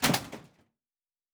Metal Foley Impact 2.wav